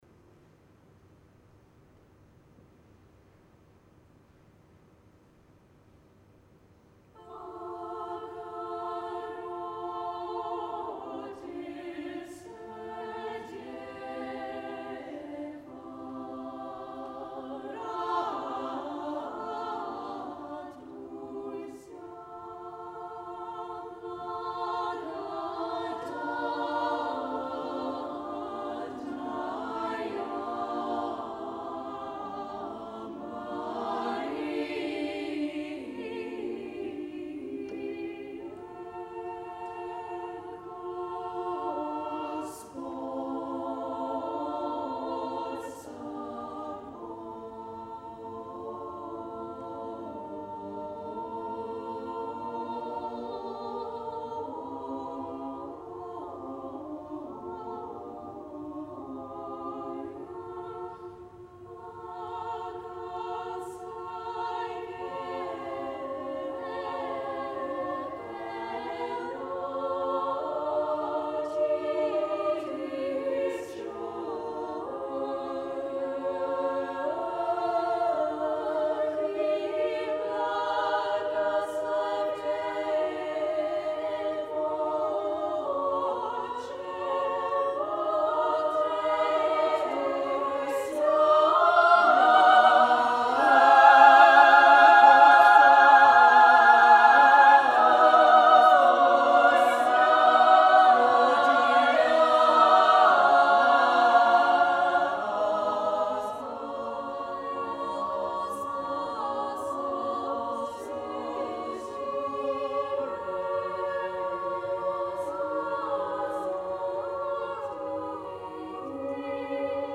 Recordings from The Big Sing National Final.
Teal Voices Wellington Girls' College Bogoroditse Dyevo (Ave Maria) Loading the player ...